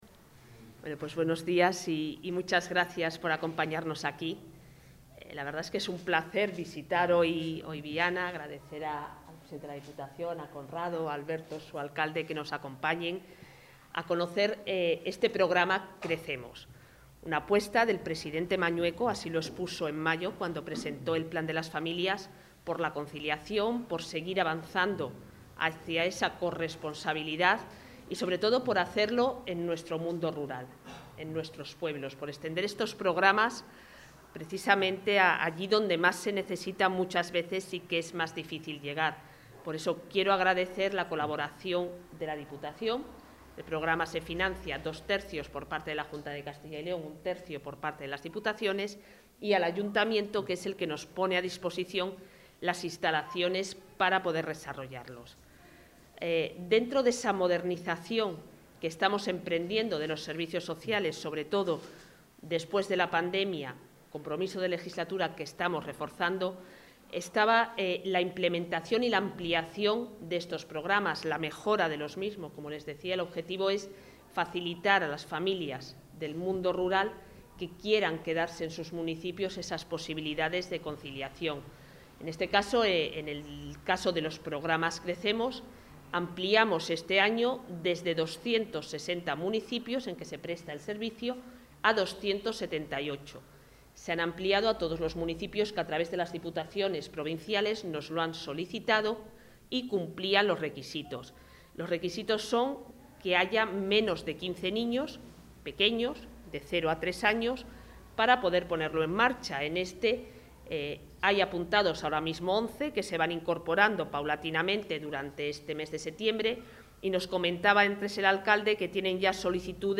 Material audiovisual de la visita de la consejera de Familia e Igualdad de Oportunidades al programa Crecemos en Viana de Cega
Declaraciones de la consejera de Familia e Igualdad de Oportunidades Visita al programa Crecemos en Viana de Cega Visita al programa Crecemos en Viana de Cega Visita al programa Crecemos en Viana de Cega Visita al programa Crecemos en Viana de Cega